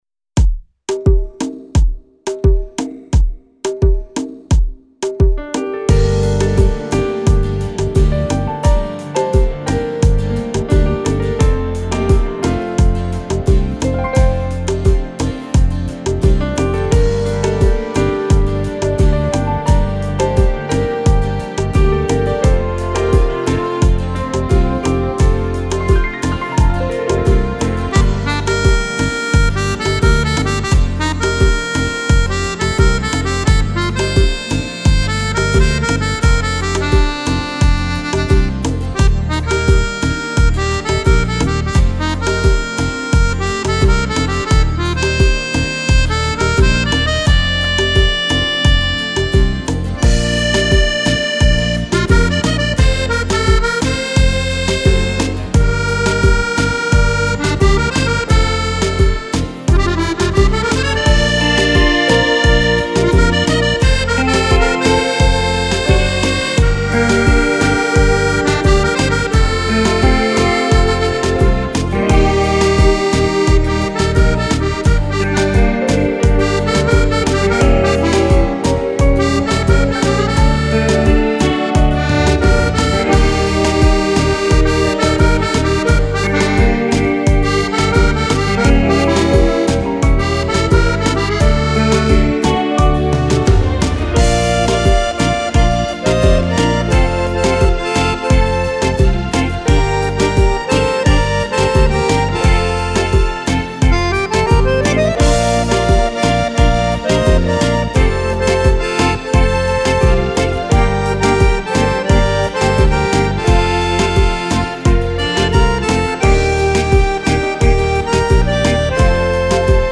Kizomba
Album di ballabili  per Fisarmonica.